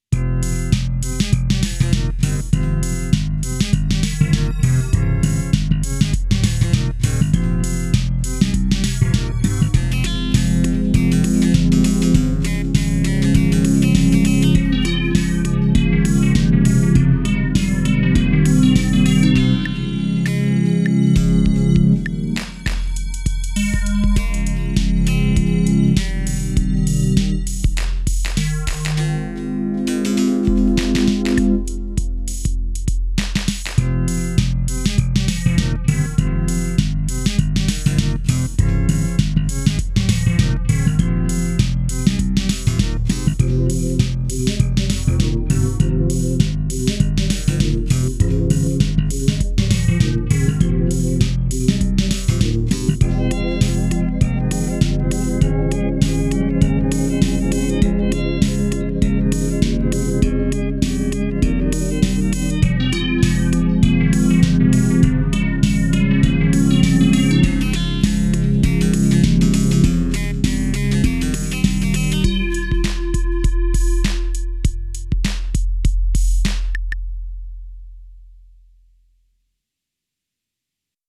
Korg M01D, 3DS